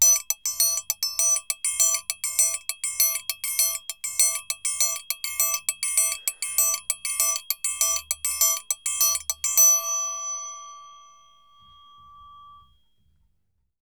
Triangle_Samba 100_2.wav